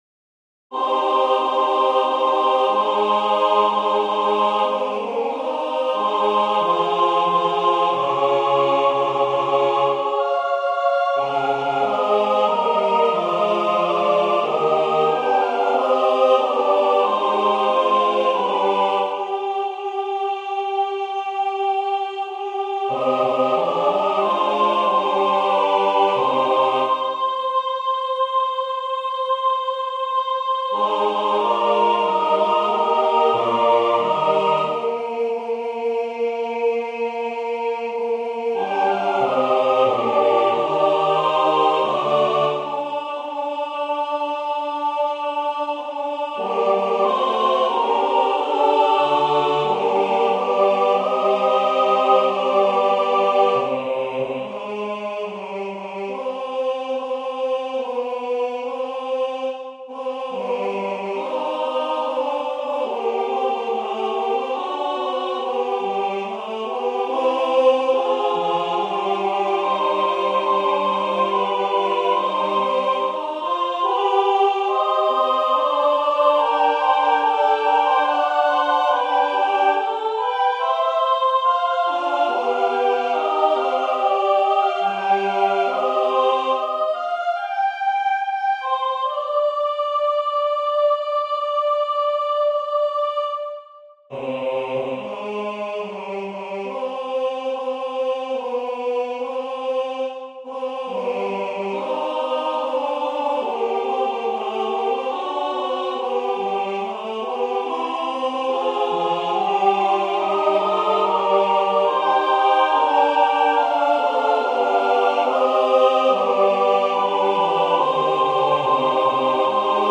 Georg Philipp Telemann Baroque né à Magdebourgle 14 mars 1681 mort à Hambourg le 25 juin 1767 Qui est-ce ? Trauer-Kantate Sonate Sop Alt Ten Bas Tutti Adagio Sop Alt Ten Bas Tutti Attention ! les interventions rapides des solistes sont incluses. A partir de la mesure 22 de la première partie le tempo est volontairement ralenti afin de bien saisir les notes en guirlande.